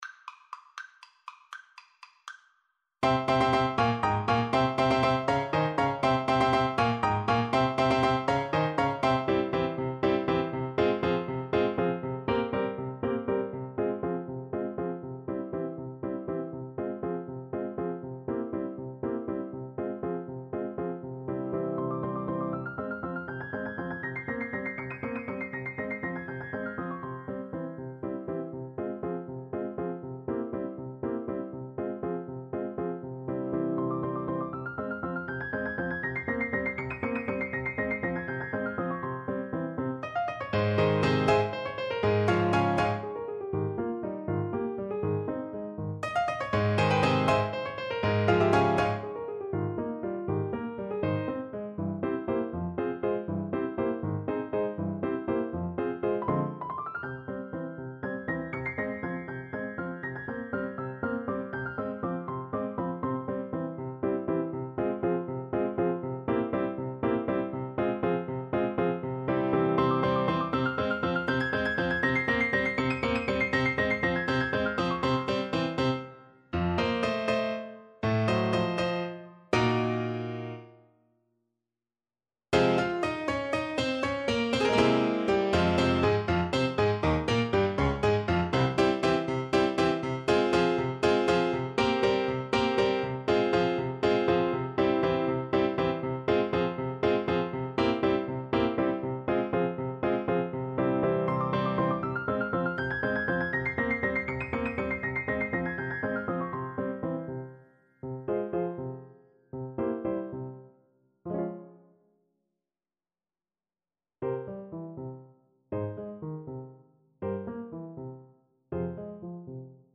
Play (or use space bar on your keyboard) Pause Music Playalong - Piano Accompaniment Playalong Band Accompaniment not yet available transpose reset tempo print settings full screen
Allegro vivo (.=80) (View more music marked Allegro)
3/8 (View more 3/8 Music)
Classical (View more Classical Saxophone Music)